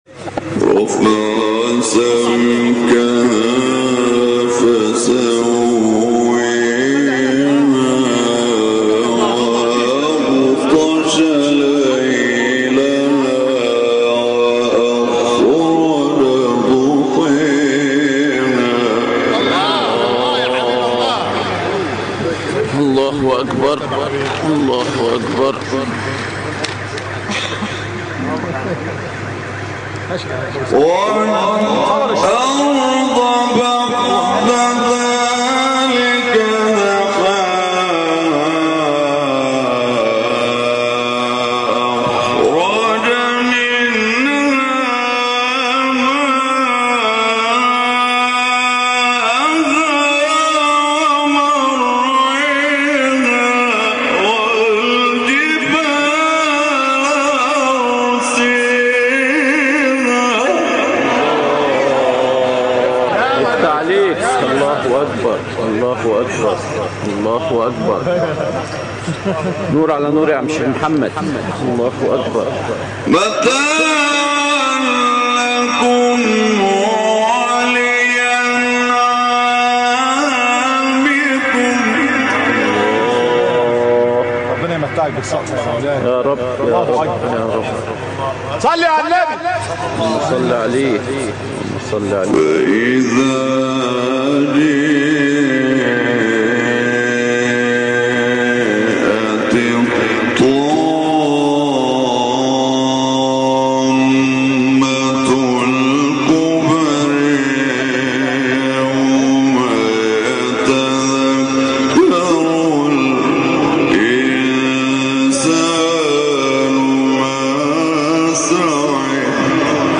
مقام : مرکب خوانی (بیات * سه گاه * رست * بیات)